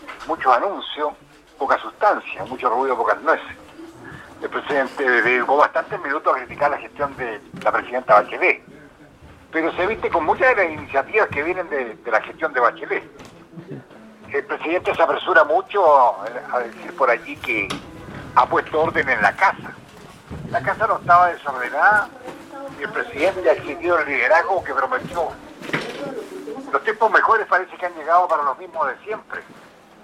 Senador PS, Rabindranath Quinteros.